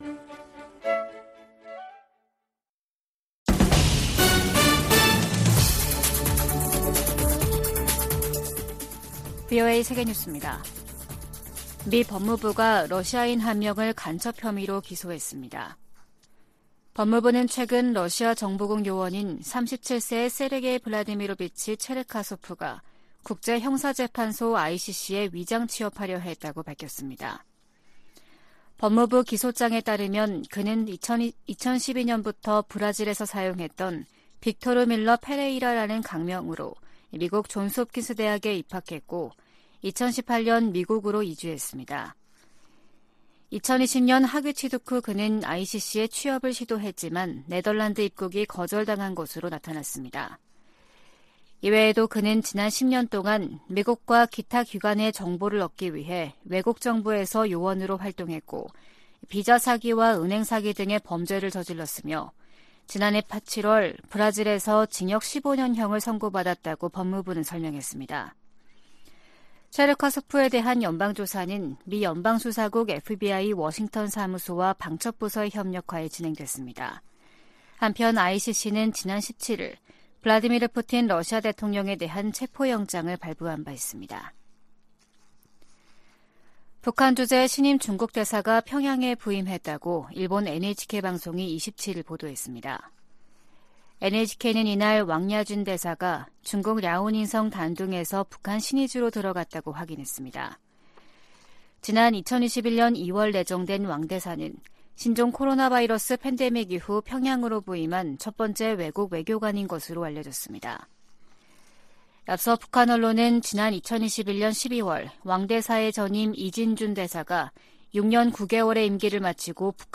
VOA 한국어 아침 뉴스 프로그램 '워싱턴 뉴스 광장' 2023년 3월 28일 방송입니다. 북한이 또 다시 단거리 탄도미사일(SRBM) 두 발을 동해상으로 발사했습니다. 미 국방부는 북한의 수중 핵폭발 시험에 대해 우려를 나타내고, 한국과 다양한 훈련을 계속 수행할 것이라고 밝혔습니다. 미 공화당 중진 상원의원이 한국에 핵무기를 재배치하는 방안을 고려해야 한다고 주장했습니다.